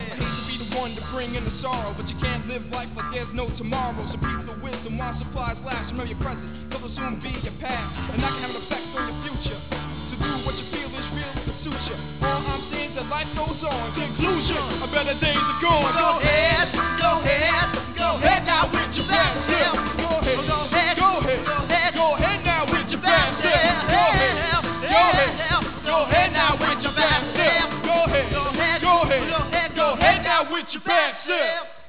auxiliary percussion
guitar